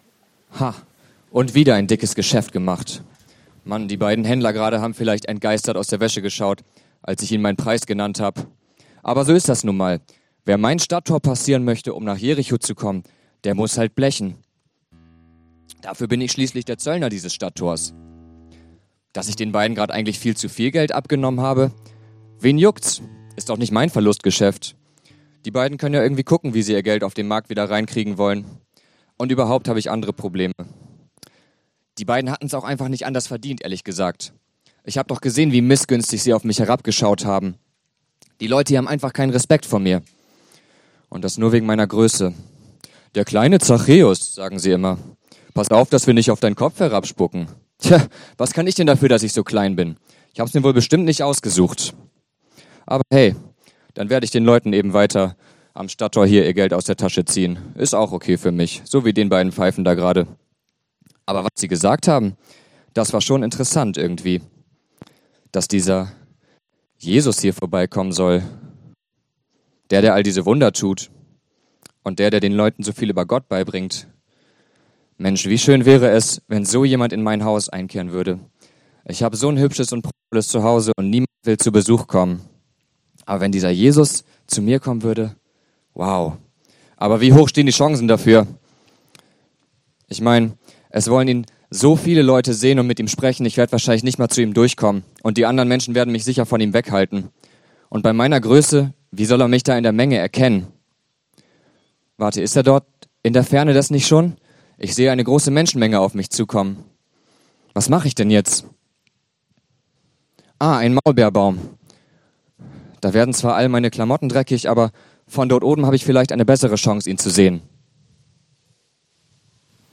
wie Jesus mein Leben veränderte Passage: Lukas 19,1-10/ Jakobus 2,17-18/ Offenbarung 3,20 Dienstart: Predigt